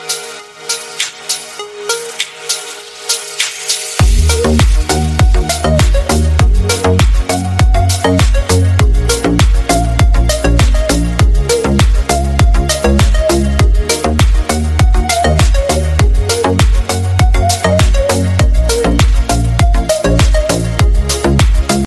نغمة هاتف عالية جدا